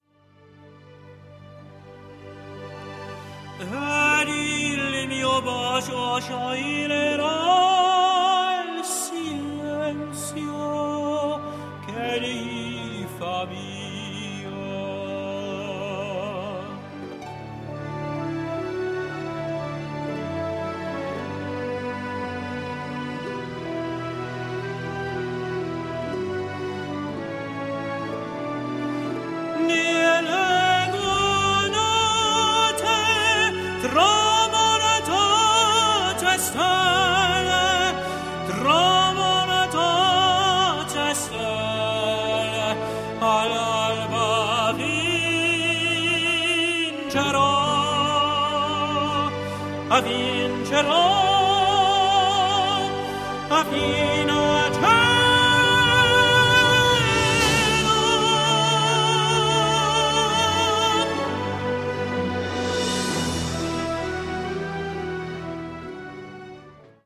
Operatic tenor